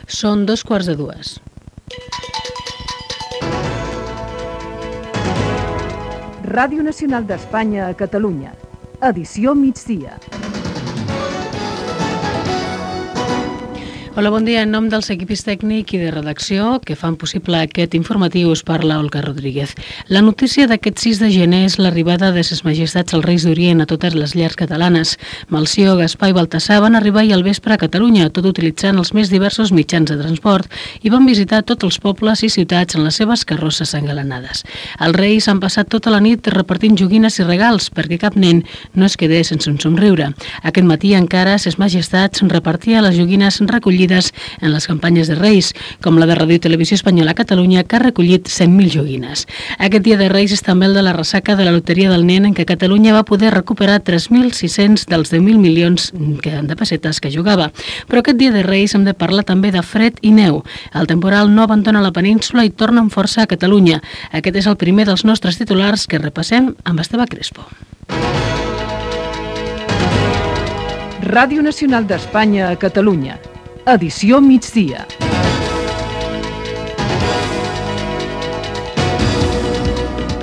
Hora. Careta del programa. Arribada dels Reis Mags d'Orient a Catalunya, loteria del Nen, temporal de neu, identificació.
Informatiu